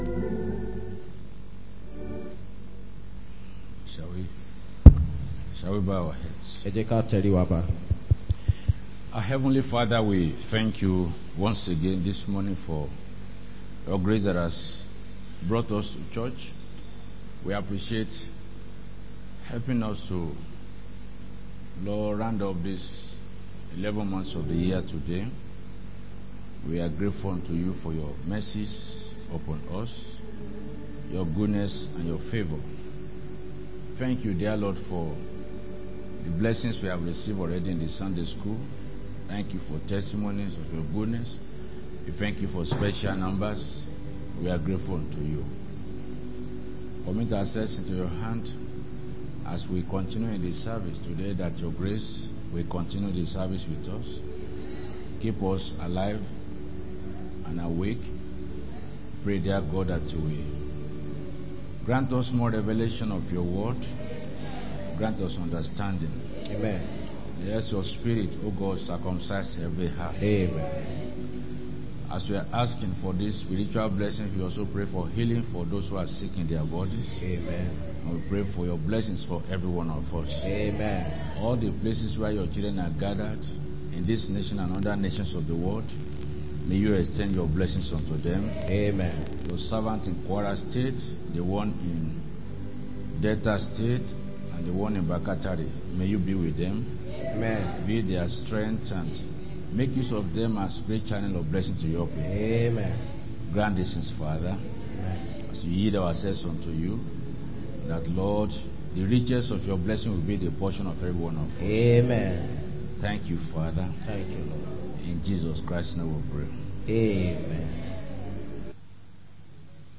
Sunday Main Service 30-11-25